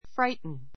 fri gh ten A2 fráitn ふ ラ イ ト ン 動詞 ひどくびっくりさせる, 怖 こわ がらせる, おびえさせる frighten birds away frighten birds away 小鳥たちをびっくりさせて飛び去らせる Our baby was very frightened by the thunder.